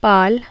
Hindi possesses contrasts between aspirated* and non-aspirated stops in both voiced and voiceless sounds.
pal.wav